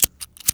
FX_EMPTYCHAMBER.WAV